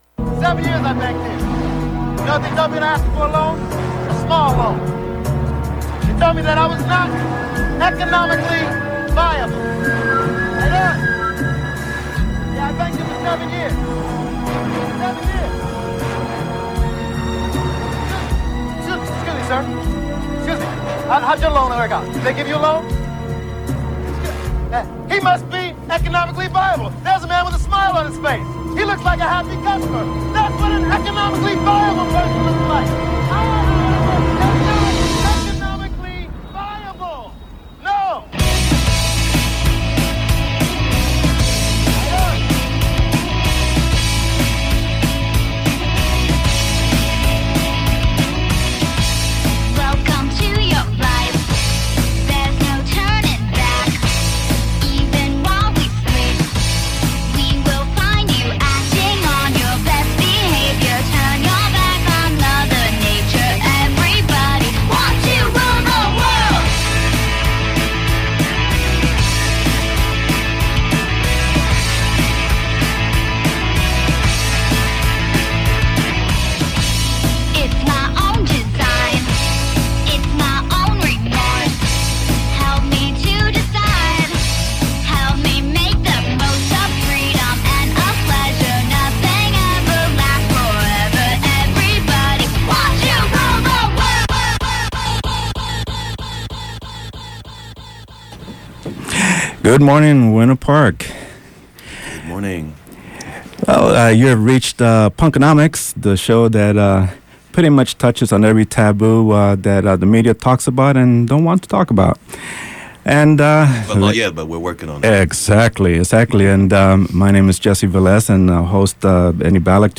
Includes a song my old band from the 80’s recorded last year (at home via internet) dedicated to all the popular uprising around the world and the righteous rage behind them.